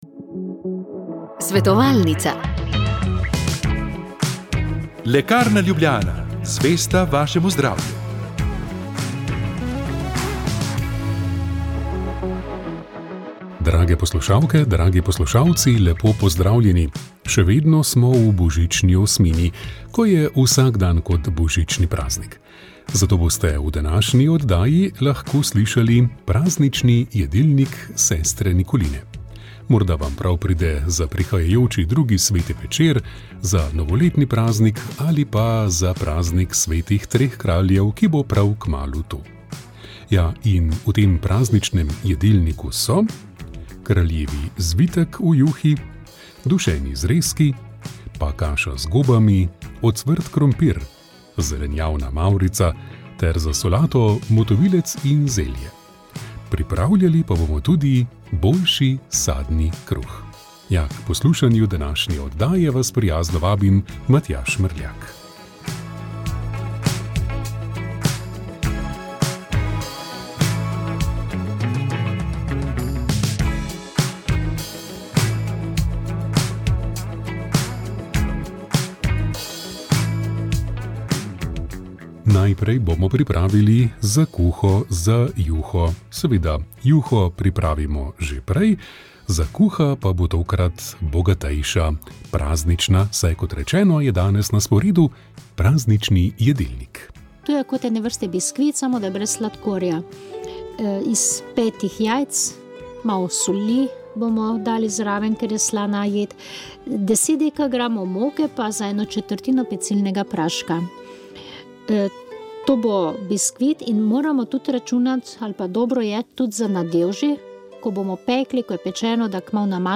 Naša želja je bila odpreti prostor za iskren in spoštljiv pogovor med teisti in ateisti. Zadnja oddaja povzema ključna spoznanja, ki jih je prinesel dialog, besedo pa je imelo tudi občinstvo, ki se je zbralo ob snemanju oddaje.